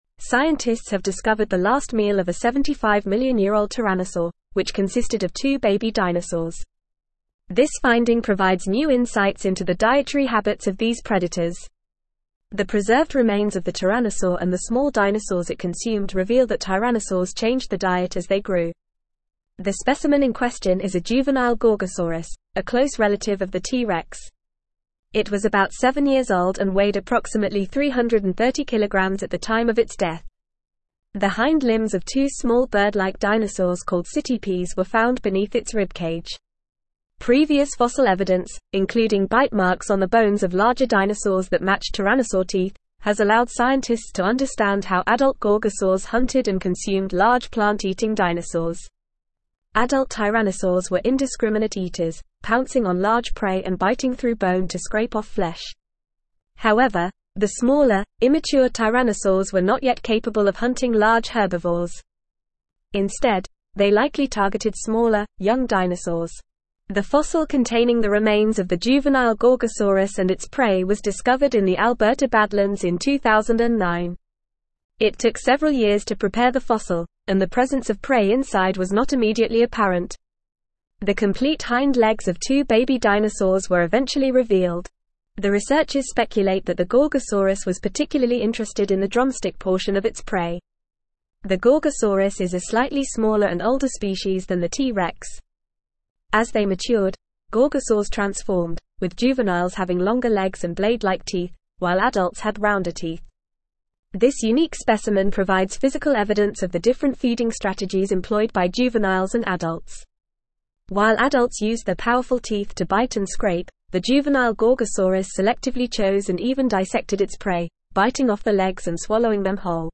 Fast
English-Newsroom-Advanced-FAST-Reading-Ancient-Tyrannosaurs-Last-Meal-Two-Baby-Dinosaurs.mp3